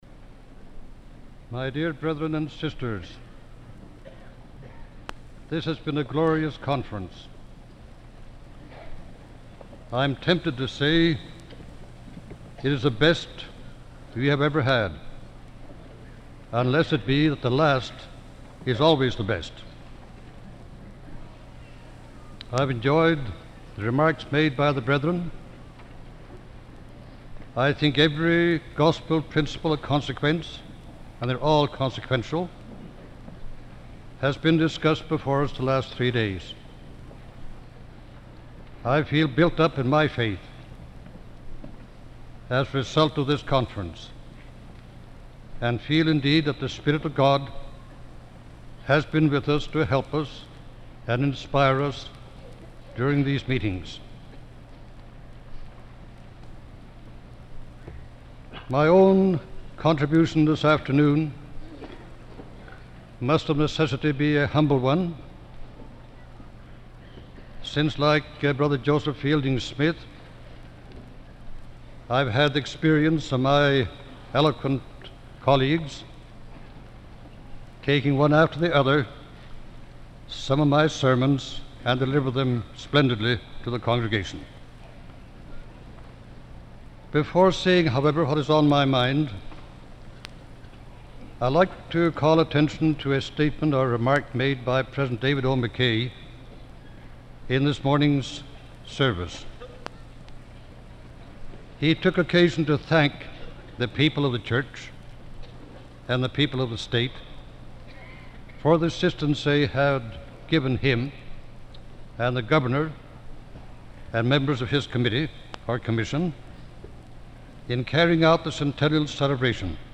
General Conference Talk